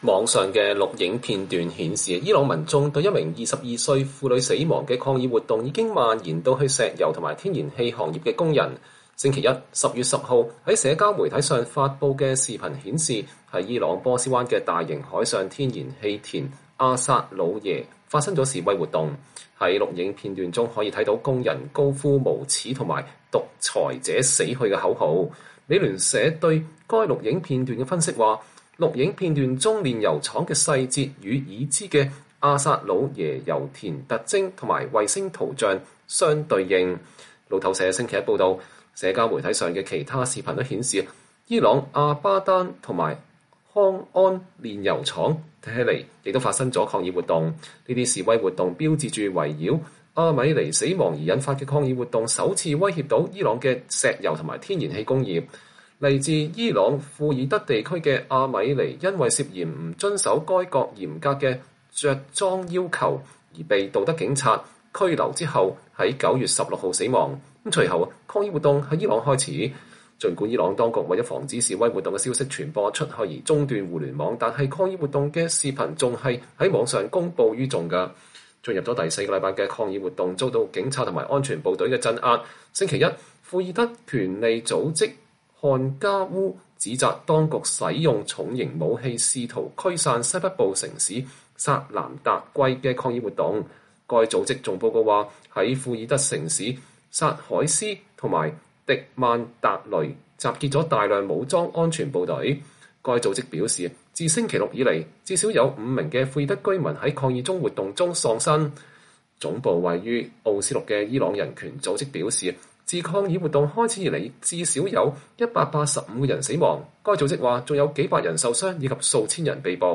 在視頻中可以看到，工人們高呼“無恥”和“獨裁者去死”的口號。